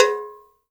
AFRO AGOGO 1.wav